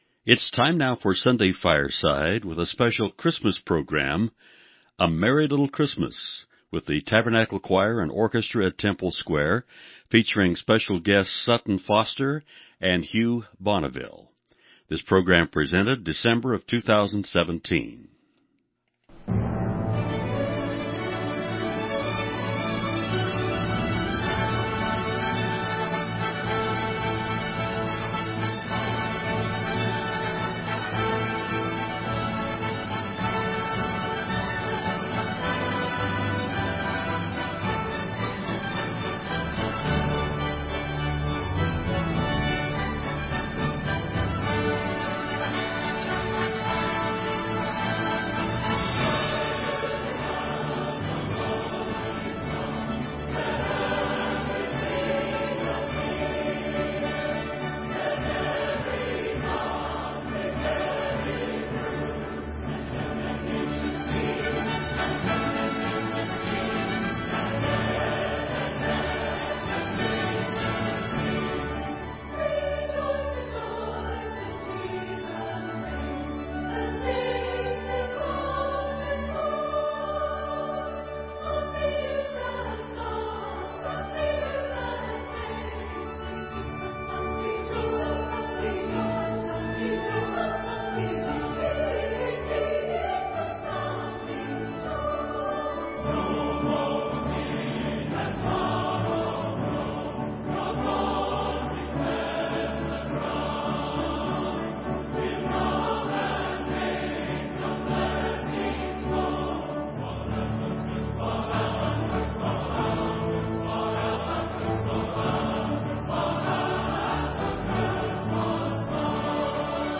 11-28 Christmas Concert